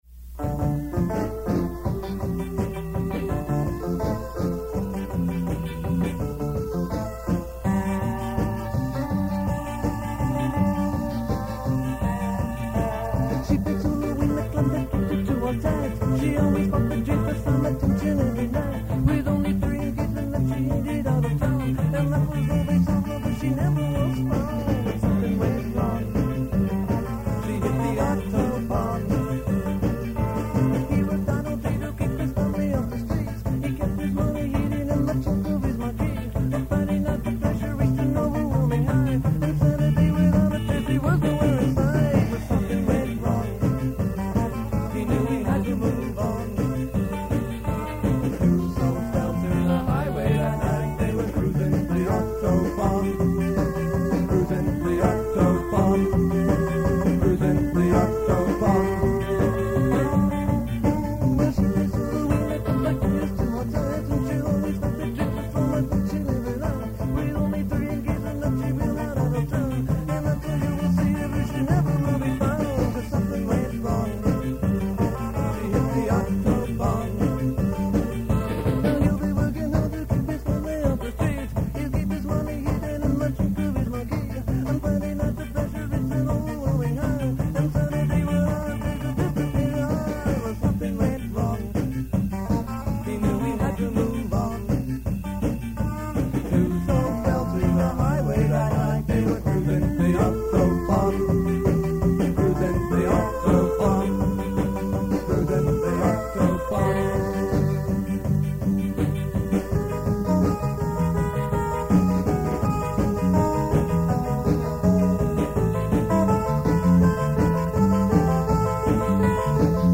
Keep in mind that these are from very old cassette tapes, so sound quailty is definitely an issue.
The band played some covers from The English Beat, Madness and The Specials, but mostly played original material in the style of ska and reggae.
written by Silent Q: from the Rehearsal tape